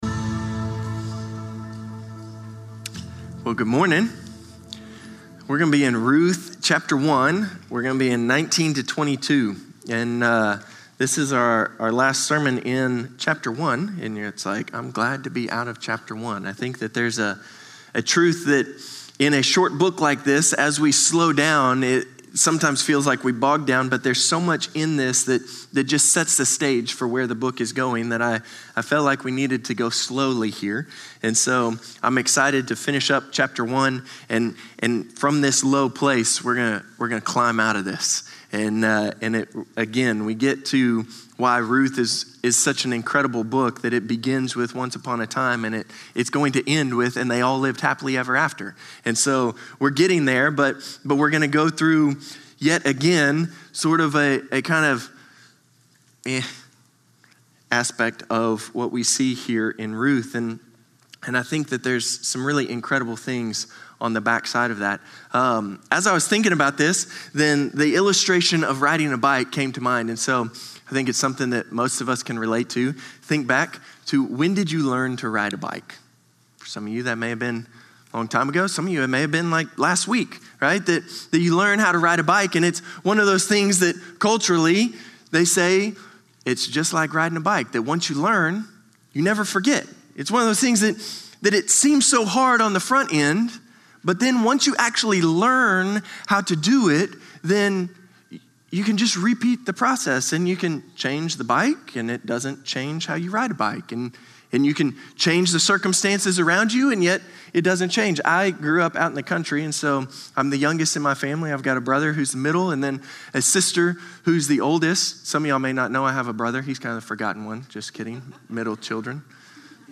Norris Ferry Sermons Aug. 31, 2025 -- The Book of Ruth -- Ruth 1:19-22 Sep 02 2025 | 00:32:37 1x 00:00 / 00:32:37 Subscribe Share Spotify RSS Feed Share Link Embed